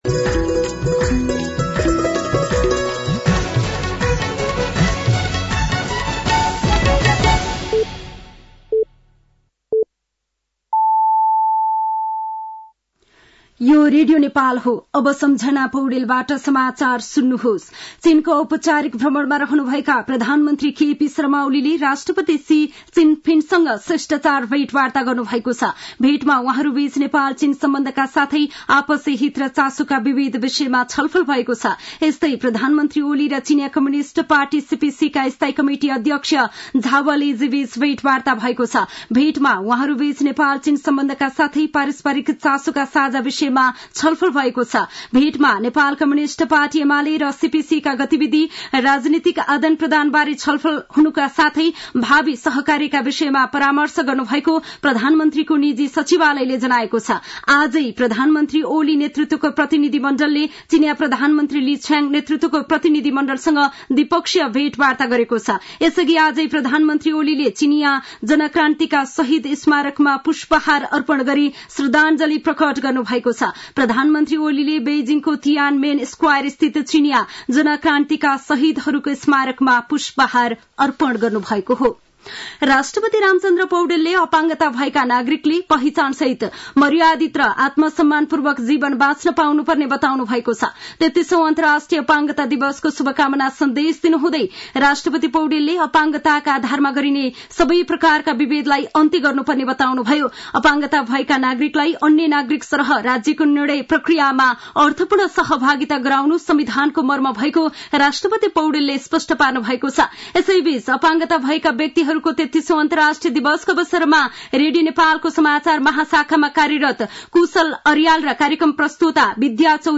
साँझ ५ बजेको नेपाली समाचार : १९ मंसिर , २०८१